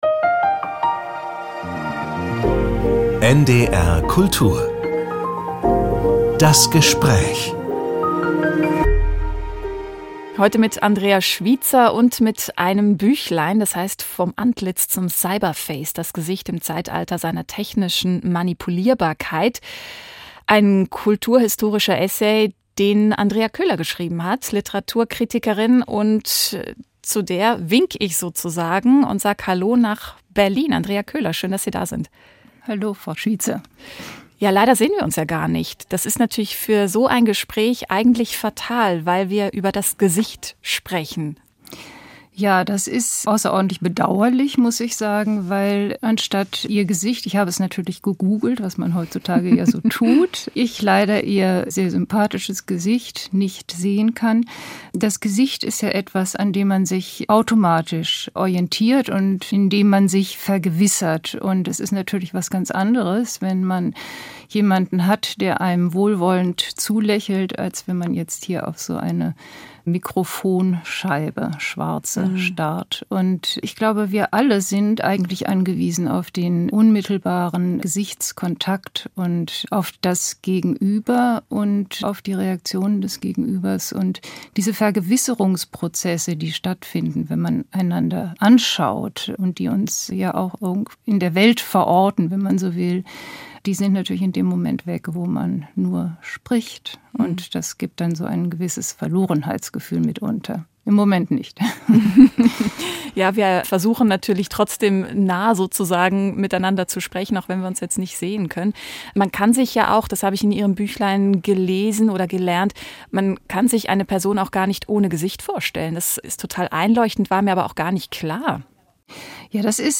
Vom Antlitz zum Cyberface - Gespräch